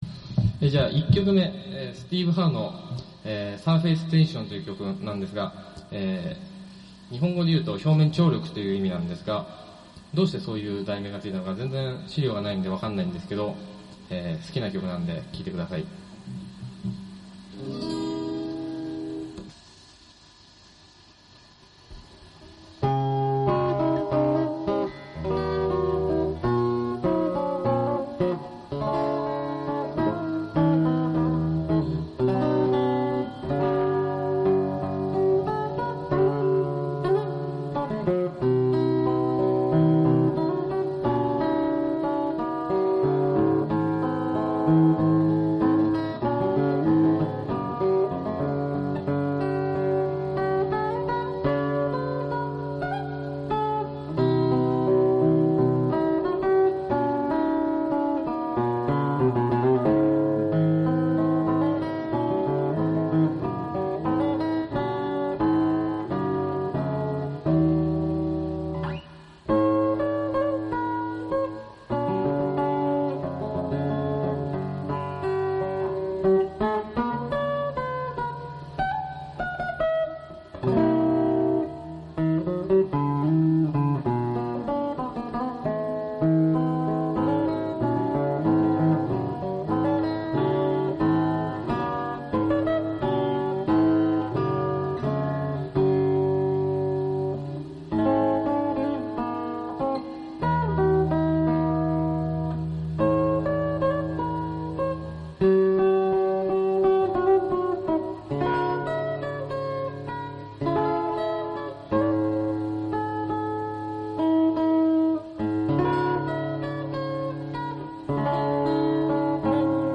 A.guitar